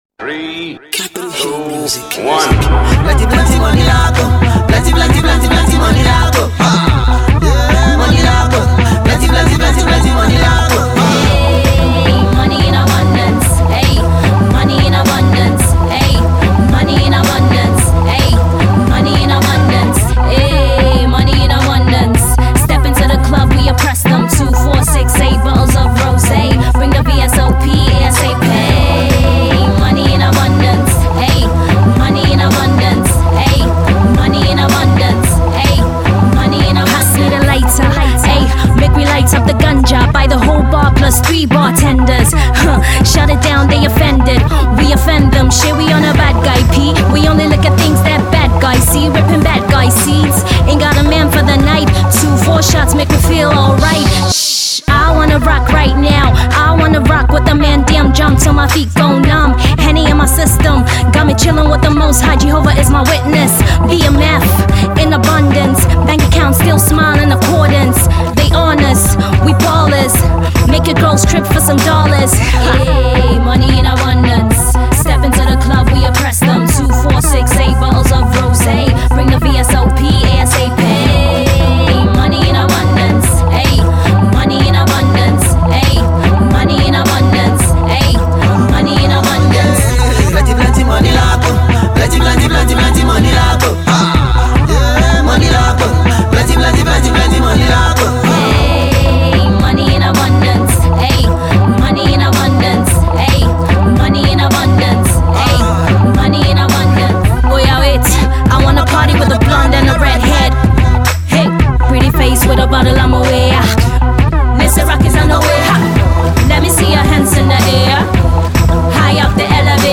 Another femcee is on rise
Check out her new Afro Hip-Hop cut